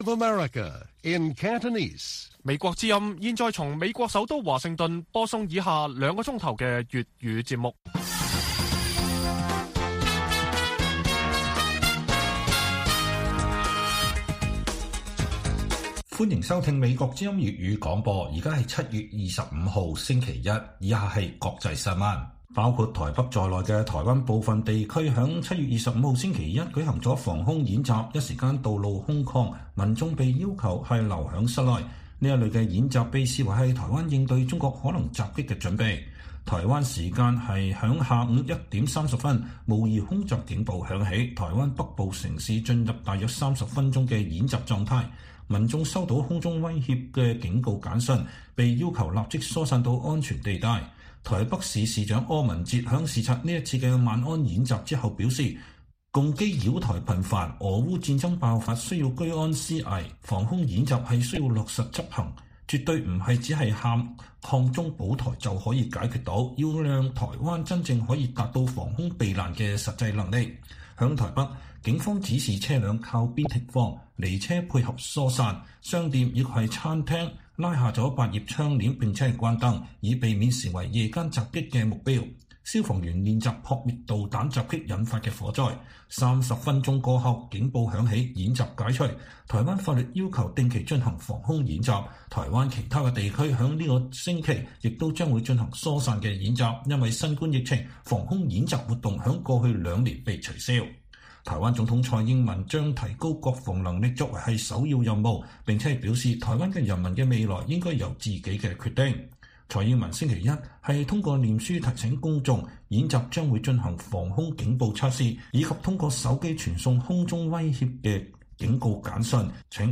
粵語新聞 晚上9-10點: 美國安顧問：在台灣問題上，“模糊必須是戰略的特徵”